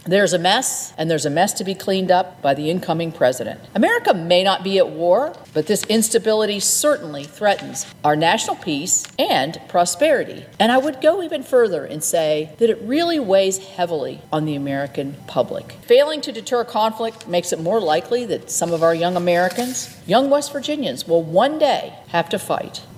The Senate Chair of the Republican Conference, West Virginia’s Shelly Moore Capito, took to the Senate floor recently to highlight the dangerous world President Biden leaves behind with weak national security policies involving China, Iran, North Korea, and Russia – policies that could affect young Americans…